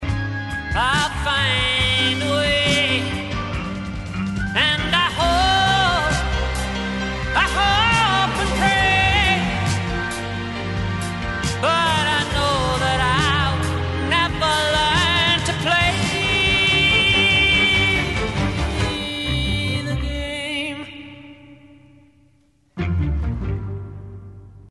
Recorded at Hérouville & Ramport Studios, London